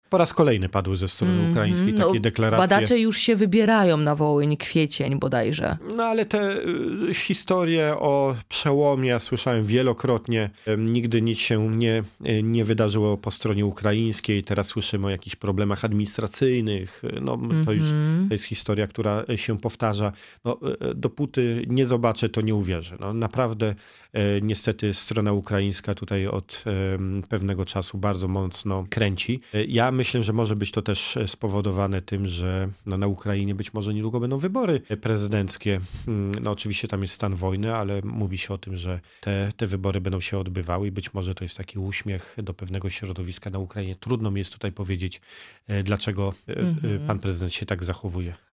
Te tematy poruszyliśmy z „Porannym Gościem” – posłem na Sejm Pawłem Hreniakiem, szefem struktur PiS w okręgu wrocławskim.